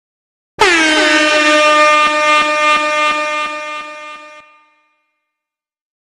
air-horn